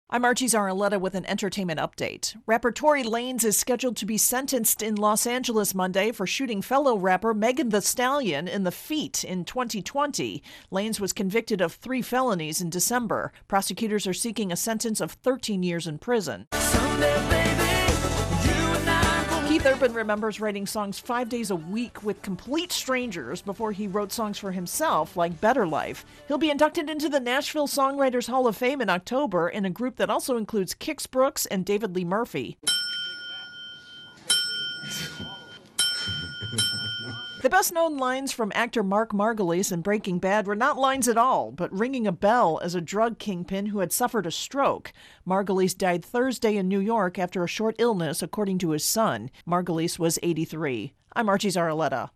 reports on the Entertainment Update.